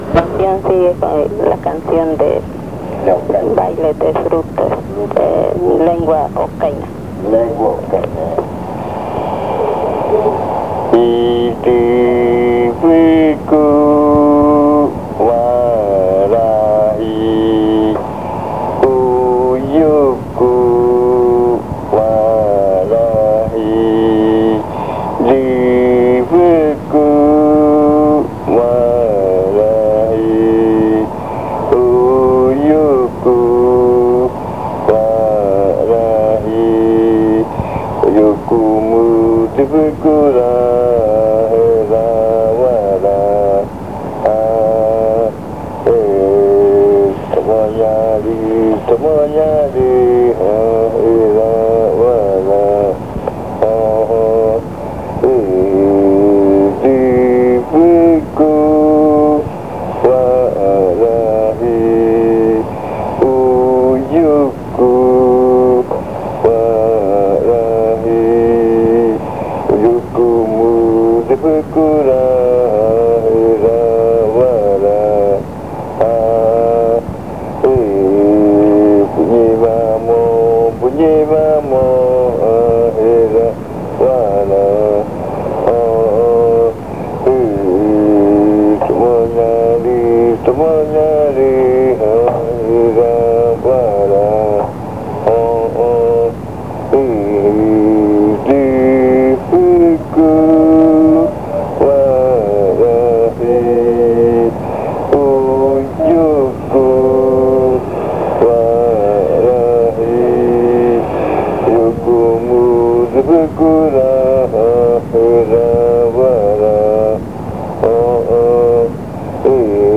Leticia, Amazonas
Entrada, arrimada. Canción cantada en ocaina.
Chant sung in Ocaina.